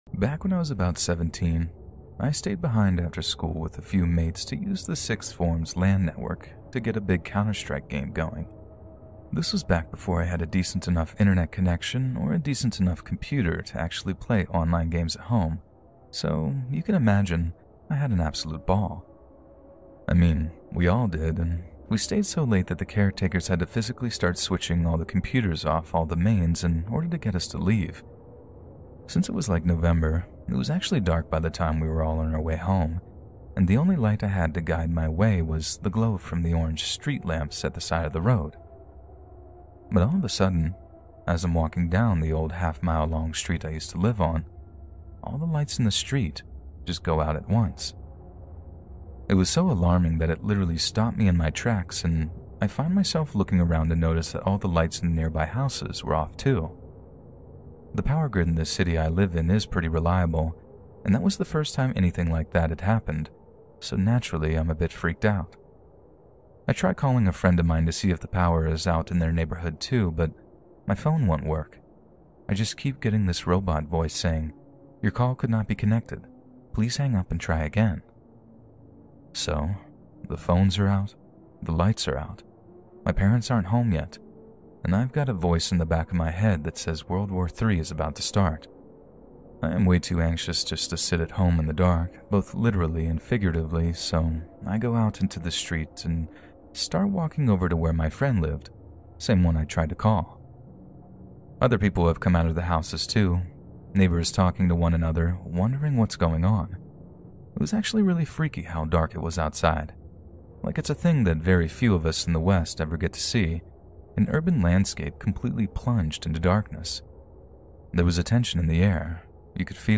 4 Blackout Horror Stories from the Dark Silence